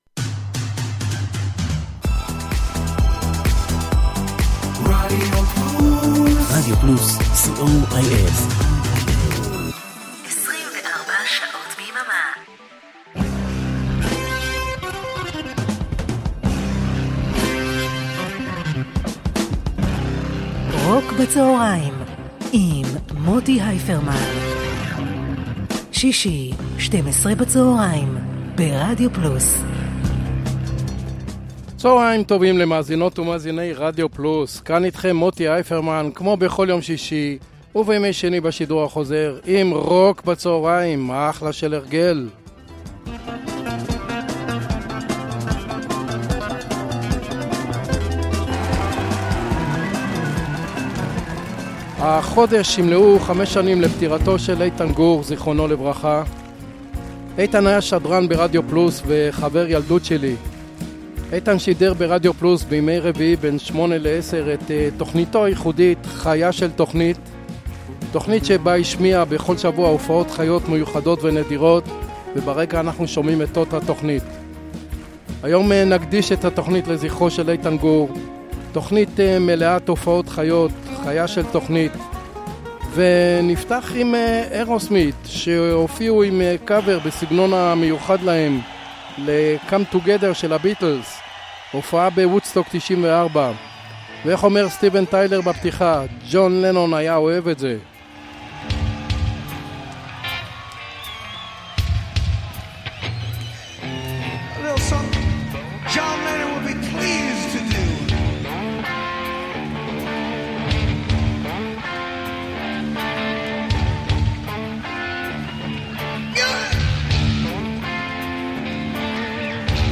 blues rock classic rock
pop rock